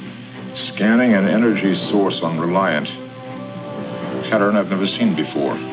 AUDIO OF SPOCK DOING A SCAN